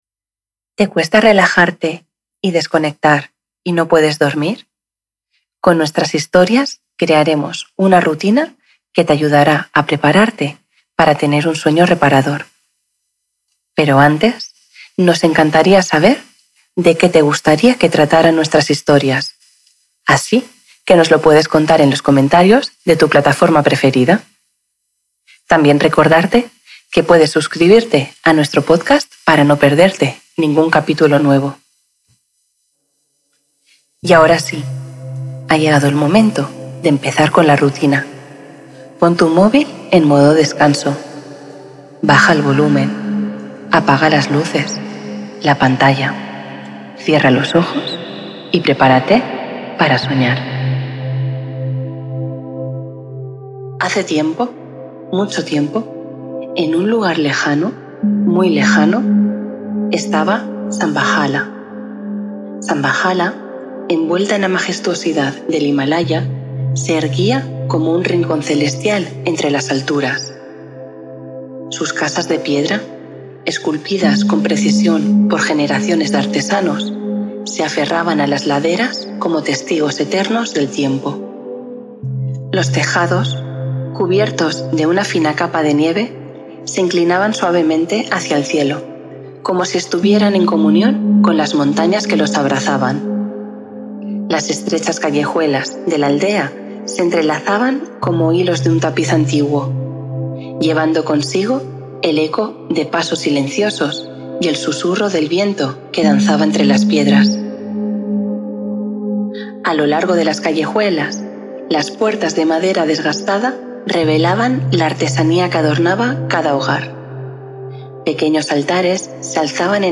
Relajante cuento nocturno.